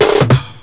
1 channel
drumroll.mp3